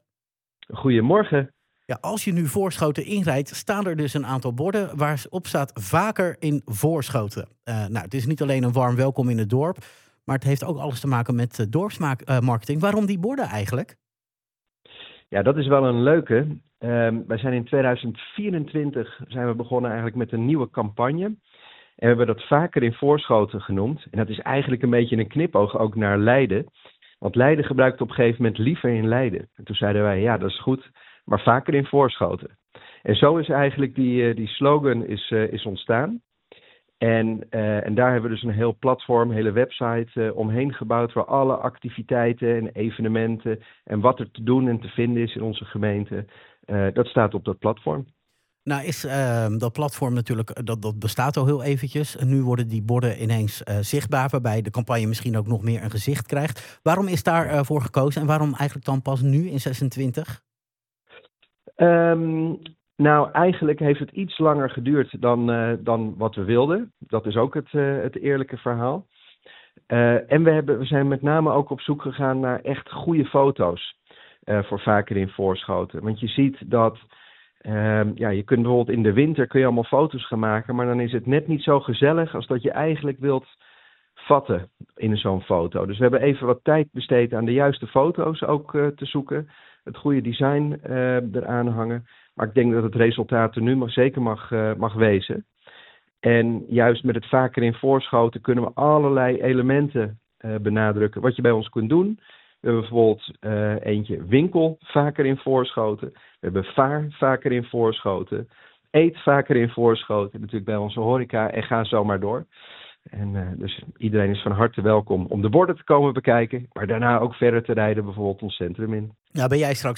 Wethouder Paul de Bruijn over de nieuwe welkomsborden: